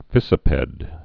(fĭsə-pĕd)